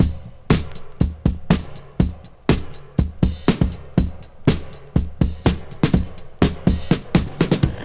• 122 Bpm Modern Breakbeat Sample D# Key.wav
Free breakbeat sample - kick tuned to the D# note. Loudest frequency: 1179Hz
122-bpm-modern-breakbeat-sample-d-sharp-key-Tv7.wav